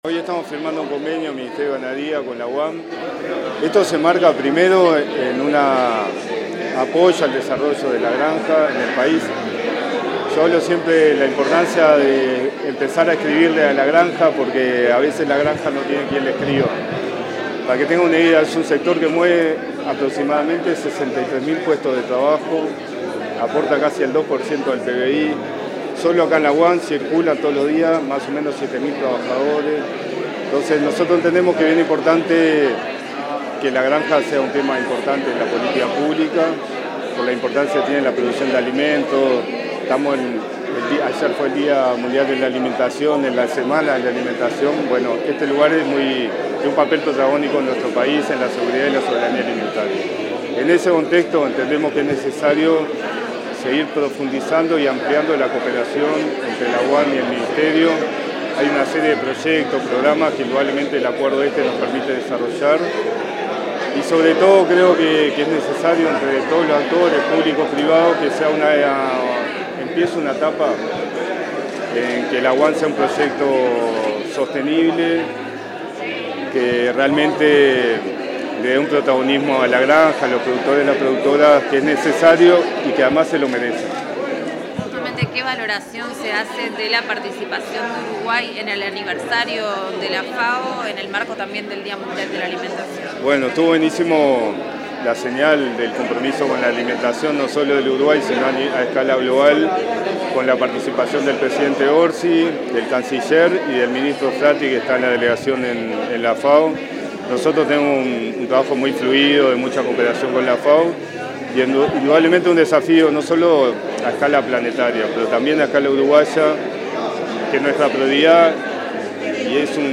Declaraciones del subsecretario del MGAP, Matías Carámbula